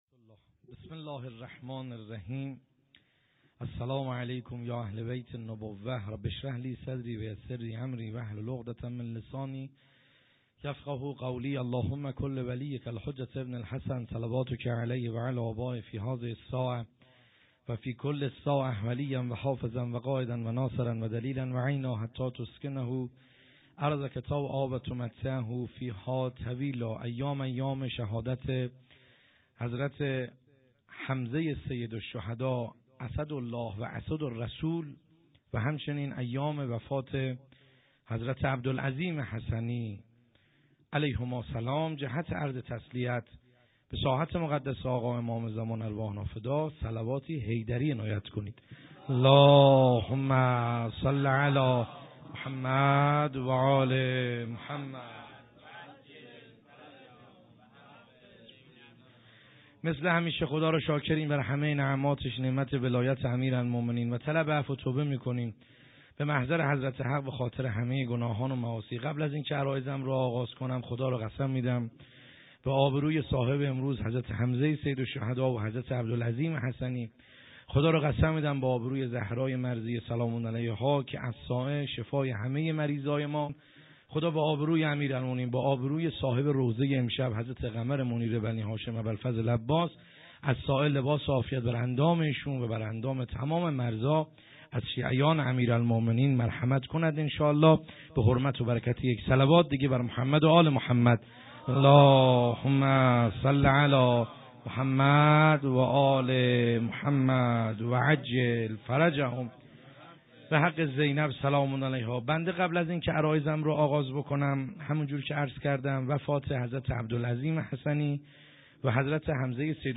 خیمه گاه - بیرق معظم محبین حضرت صاحب الزمان(عج) - سخنرانی | کیفیت موبایلی